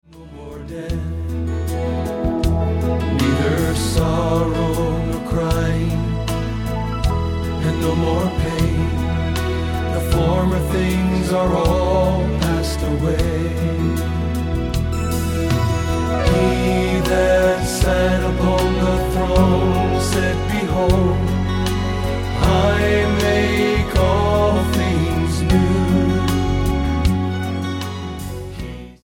STYLE: Southern Gospel